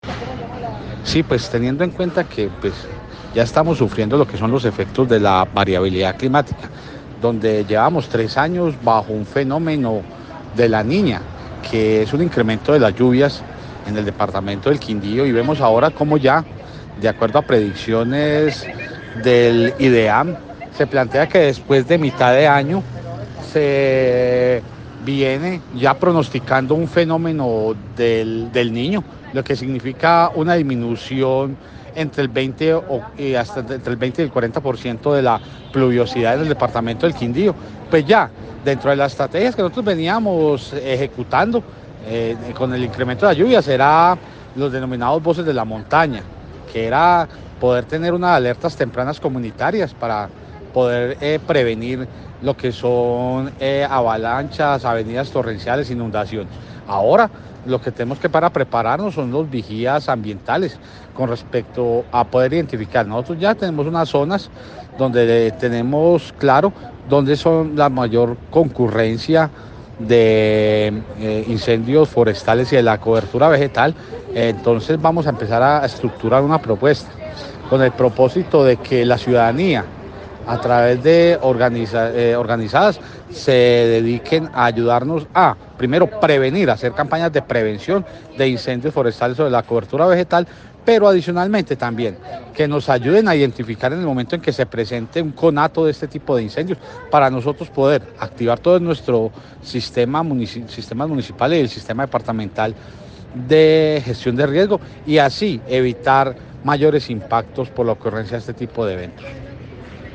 AUDIO JOSÉ MANUEL CORTÉS OROZCO-DIRECTOR GENERAL DE LA CRQ:
AUDIO_DIRECTOR_CRQ.mp3